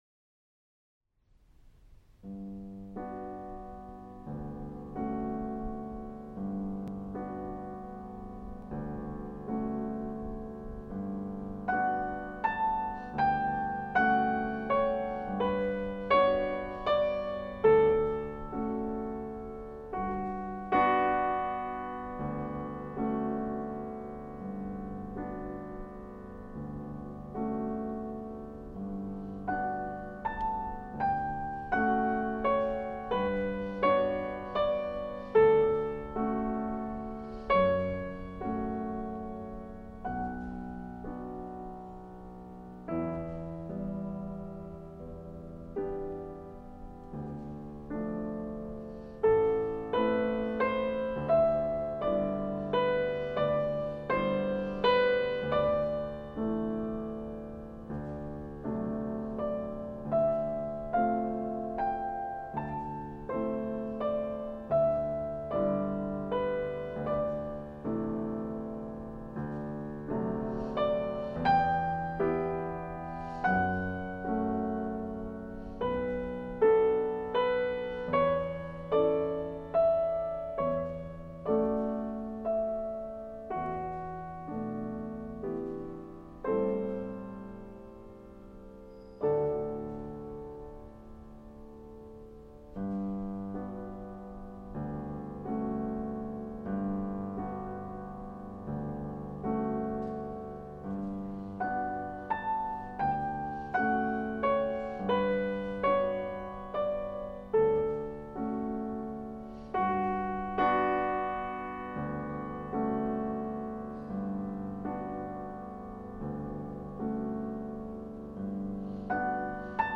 Le tre Gypnopedie che inserisco sono brani di un livello altissimo; niente virtuosismo, ma una ricerca della nota perfetta, per suscitare sentimenti molto intensi, di malinconia, di tristezza, di assoluto. Accordi in minore che toccano l’anima.